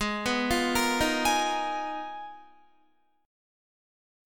Listen to Abm6add9 strummed